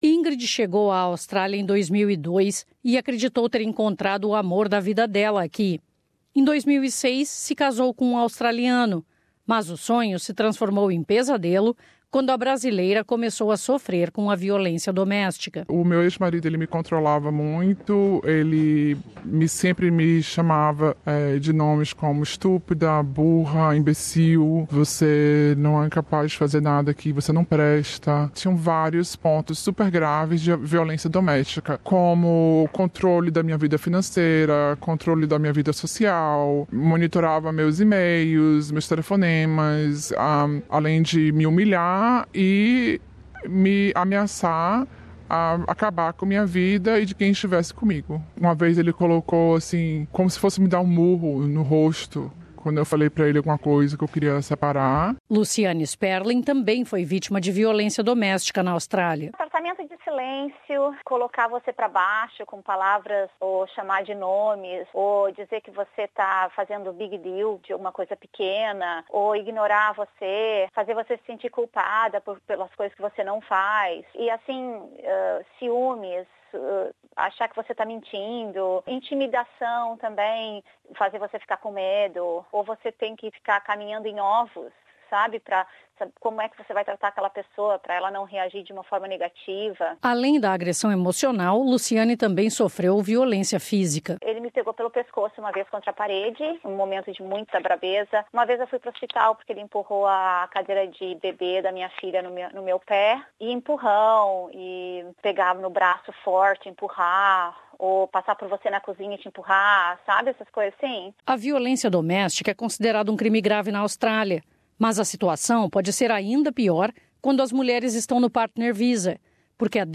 The Brazilian ladies who talked to SBS Portuguese for this special report coped with violence, overcame challenges e, now, they want to help other women to never suffer as they did. They talk about their own pain and, also, about the problem of having their life in Australia directly attached to their husbands.